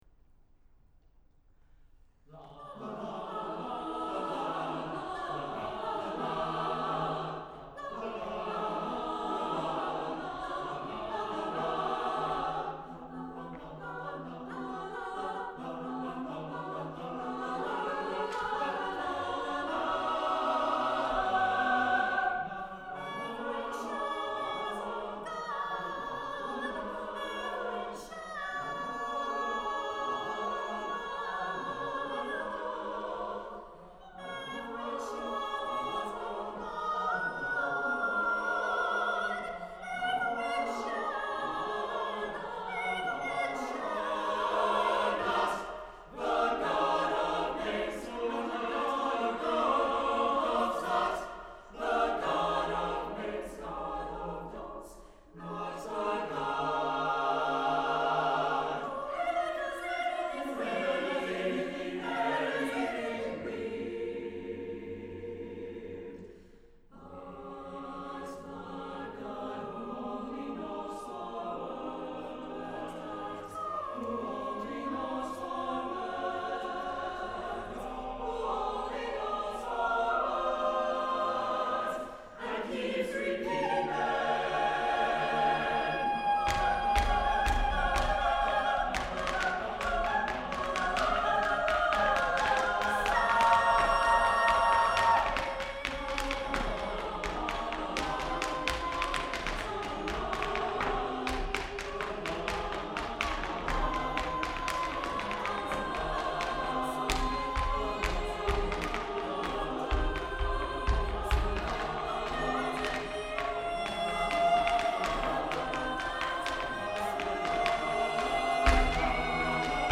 is playful, fun, highly rhythmic with soprano soli (2)